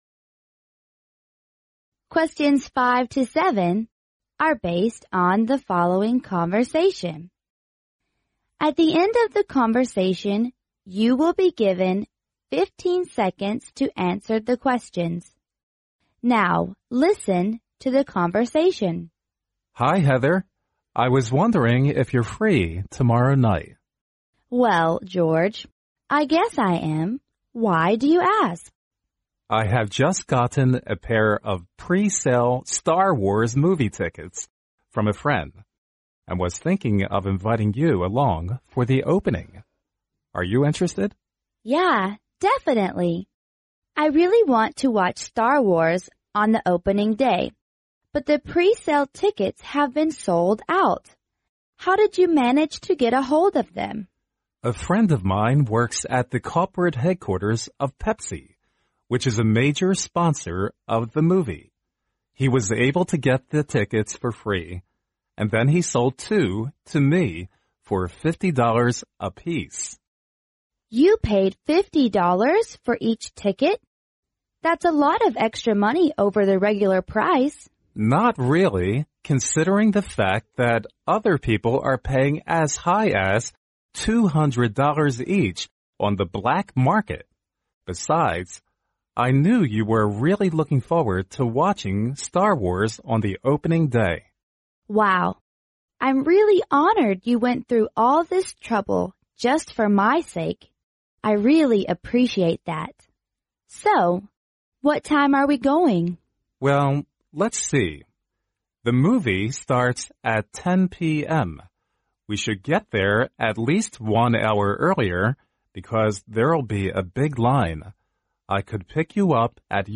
5. What is this conversation mainly about?
A. What the man and the woman are going to do tomorrow night.